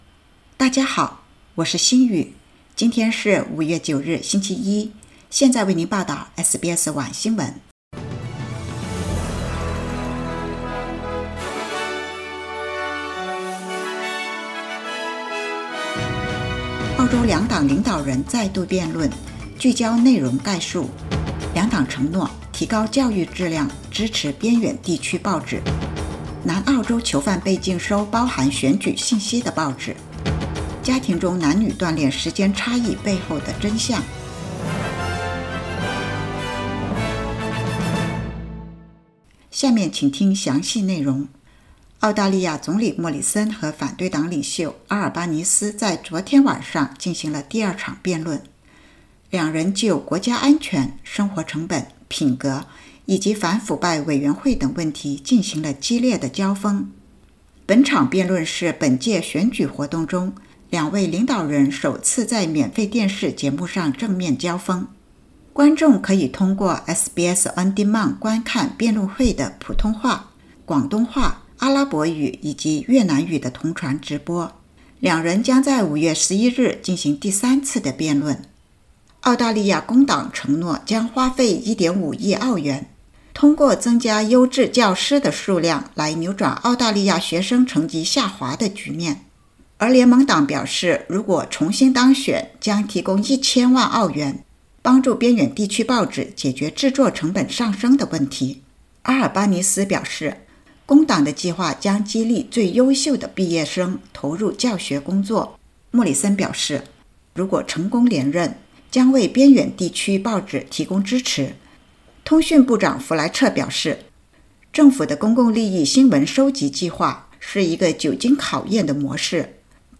SBS晚新闻（2022年5月9日）